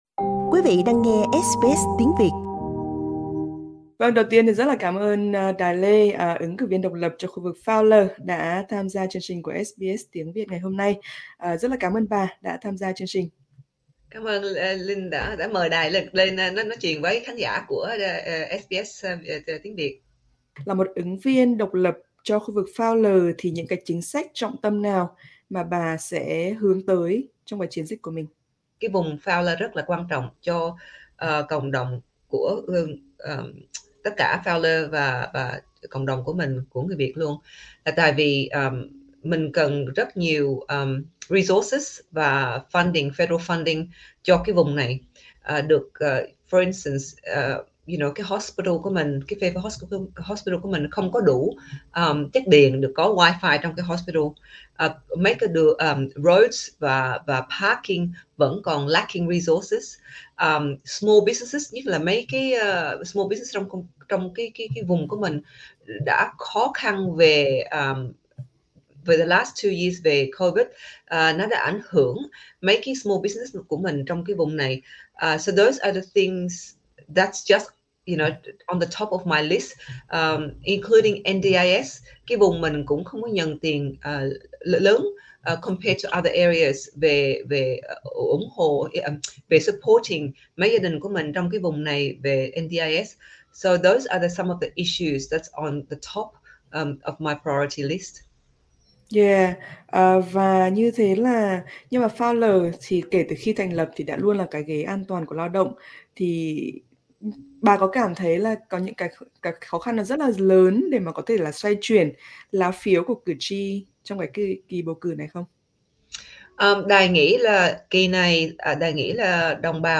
SBS Việt ngữ có cuộc phỏng vấn với bà Dai Le.